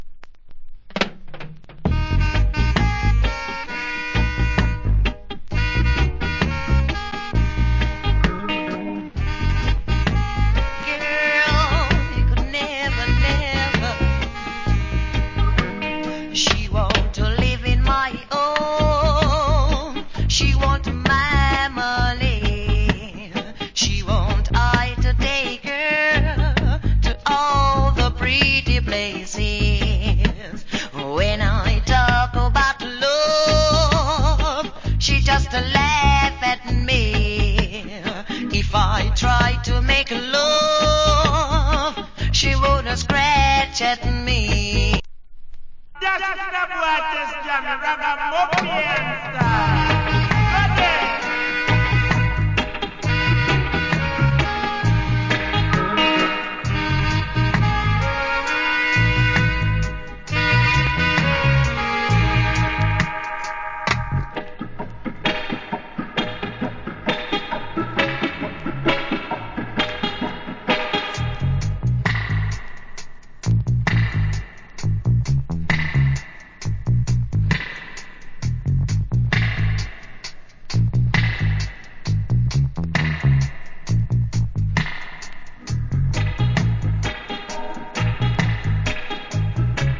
Wicked Roots Rock Vocal.